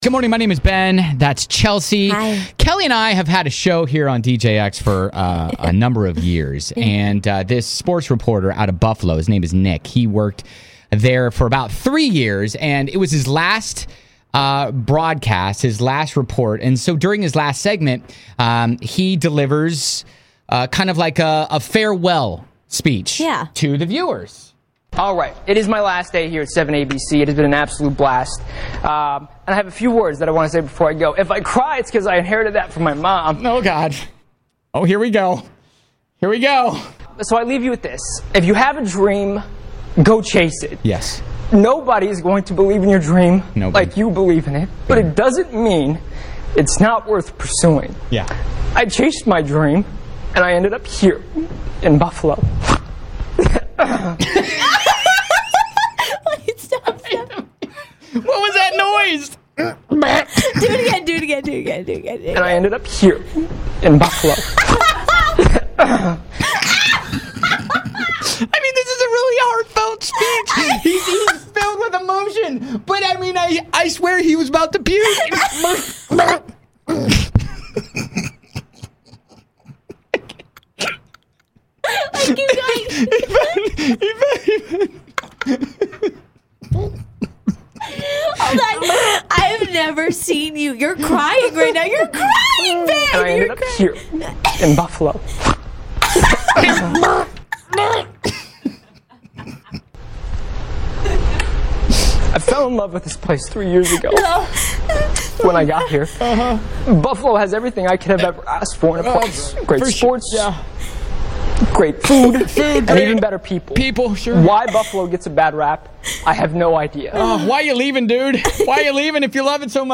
During his last segment, he delivers an Oscar-worthy speech filled with emotion, tears, choking, and Green Day lyrics.